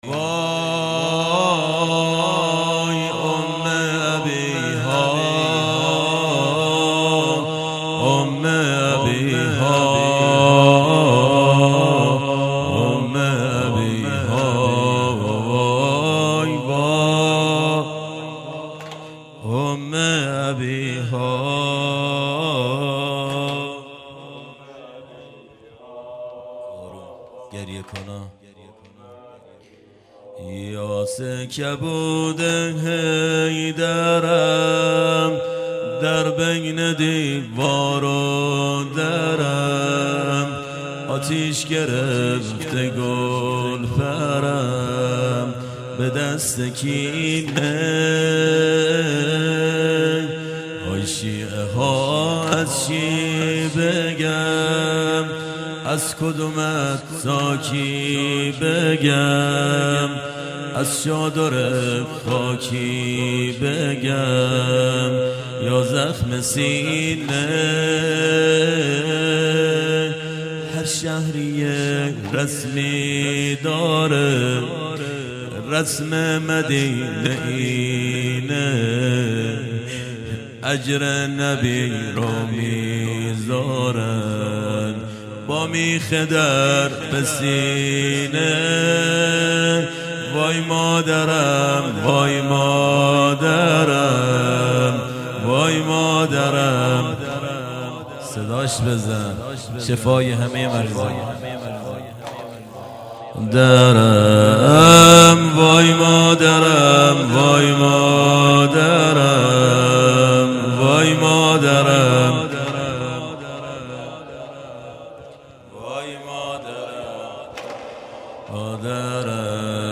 مراسم عزاداری شب اول فاطمیه دوم 1393